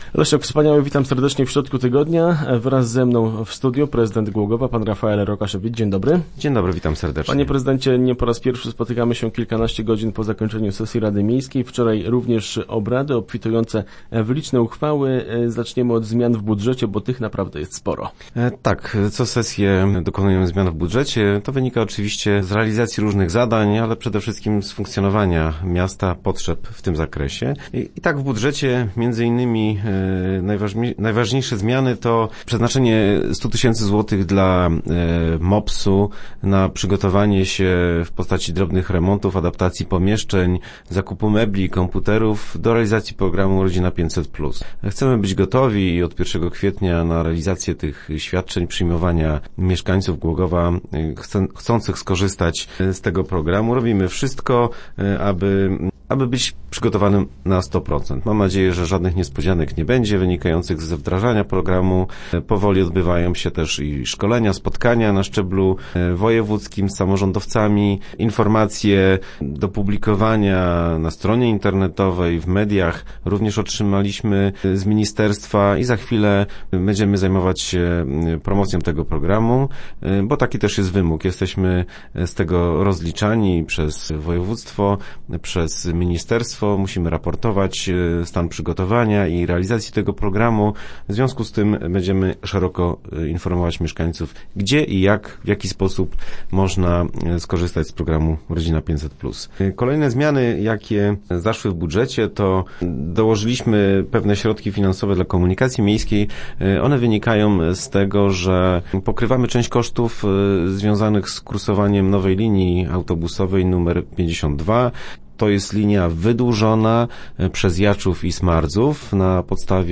0909_re_rokasz.jpgKorekta uchwały mieszkaniowej, wsparcie odbudowy zabytków, dofinansowanie imprez kulturalnych i sportowych. Między innymi o tym rozmawialiśmy w środę w studiu z prezydentem Głogowa Rafaelem Rokaszewiczem.